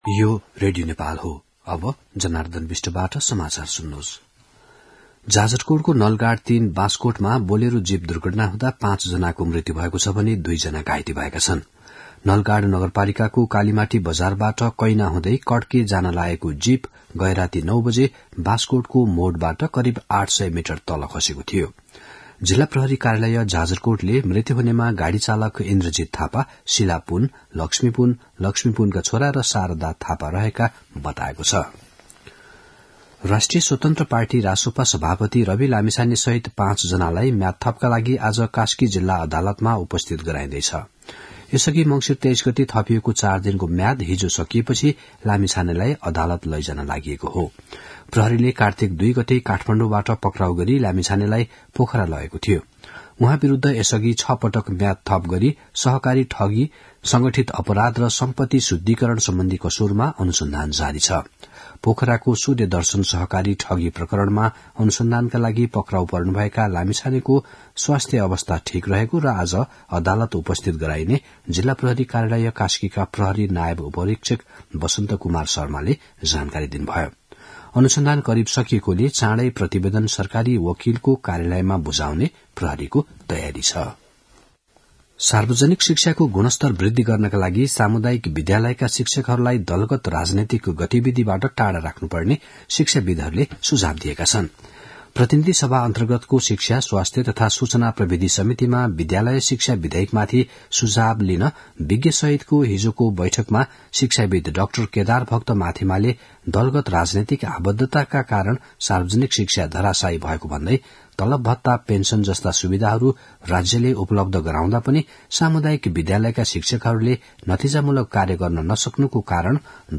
मध्यान्ह १२ बजेको नेपाली समाचार : २८ मंसिर , २०८१
12-am-nepali-news-1-9.mp3